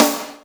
• Clicky Snare Drum D Key 89.wav
Royality free snare tuned to the D note. Loudest frequency: 3204Hz
clicky-snare-drum-d-key-89-RCR.wav